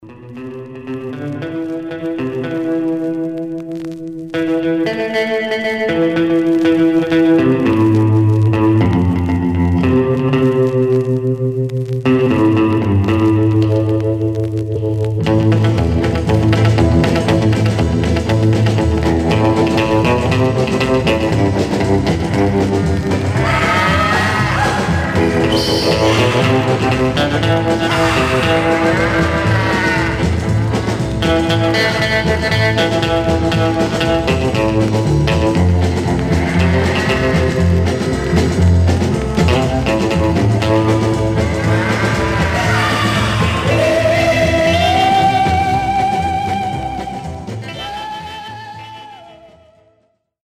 Some surface noise/wear Stereo/mono Mono
R & R Instrumental